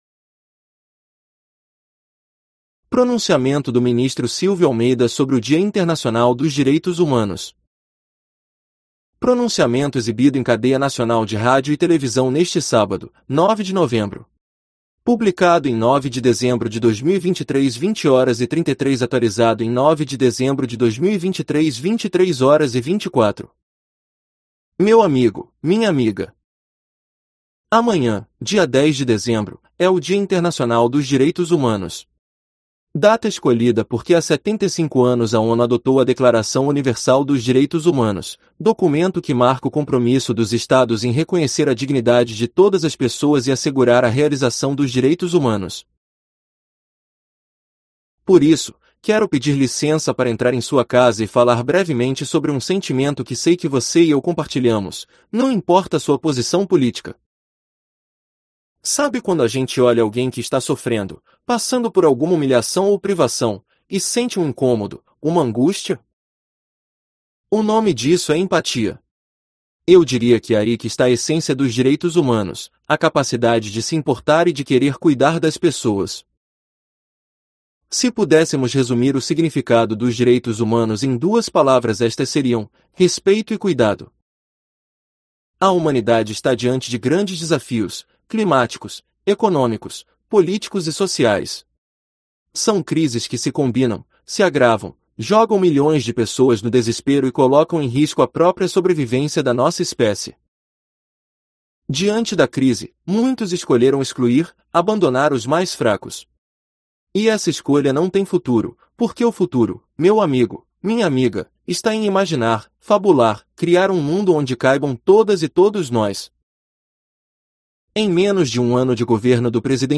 Pronunciamento do ministro Silvio Almeida sobre o Dia Internacional dos Direitos Humanos
Pronunciamento exibido em cadeia nacional de rádio e televisão neste sábado, 9 de novembro